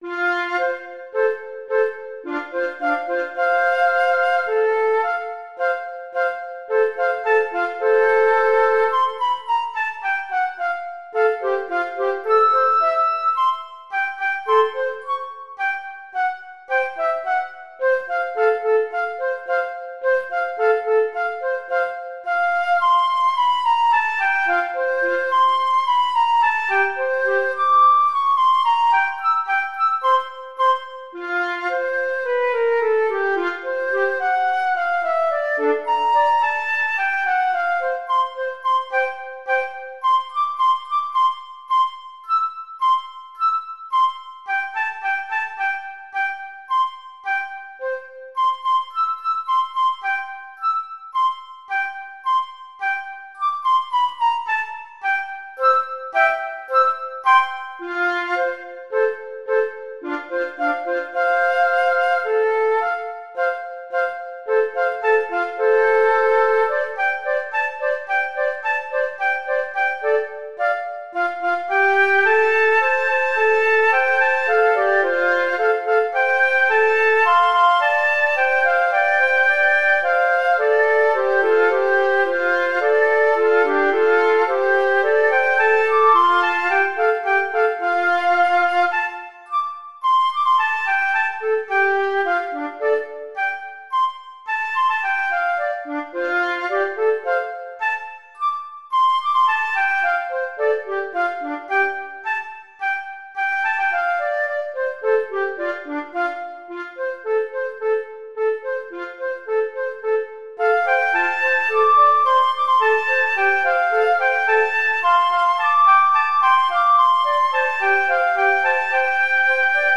9.14   尺八(d)✕2